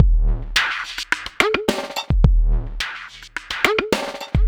BUZZER    -L.wav